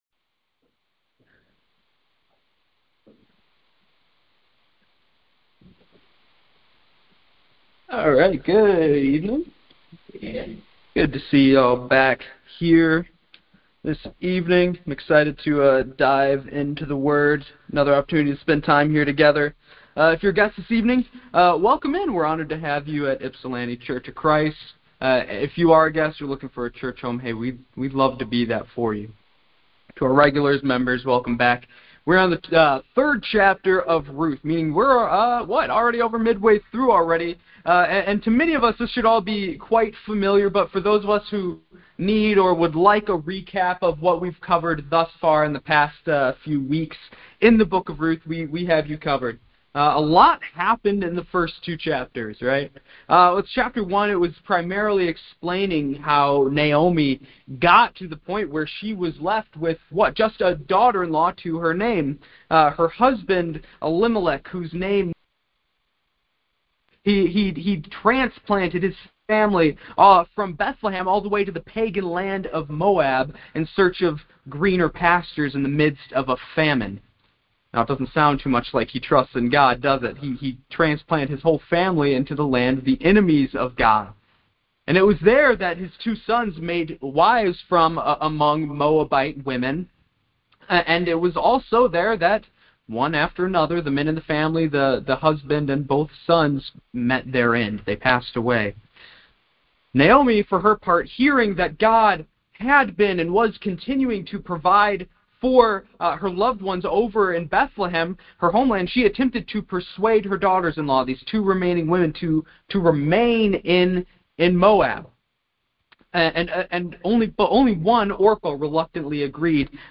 Ypsilanti Church of Christ – Sunday Evening Service 9.7.25